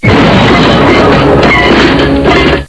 crash.wav